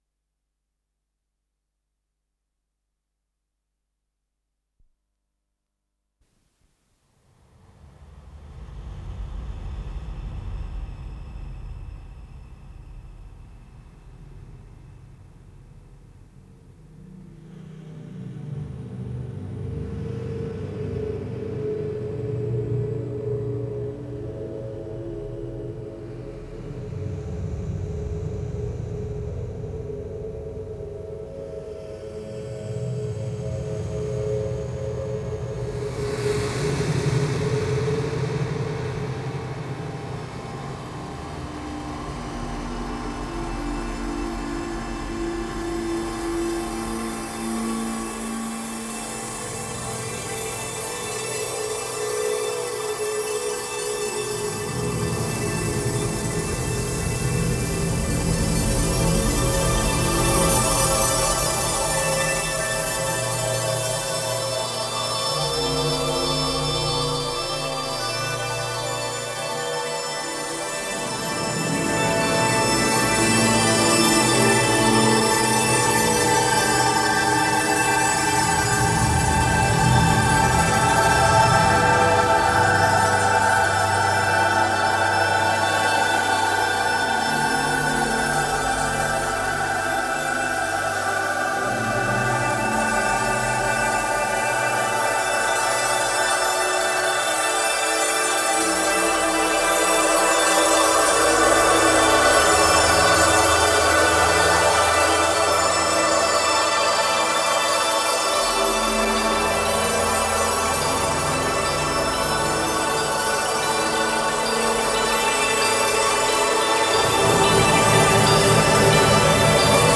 синтезаторы